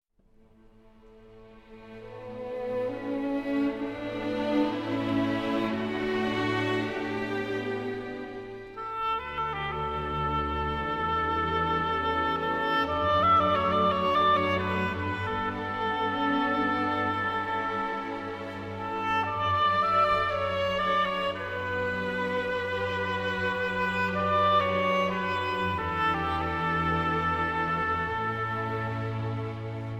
the lyrical oboe in opera and cinema
oboe, oboe d'amore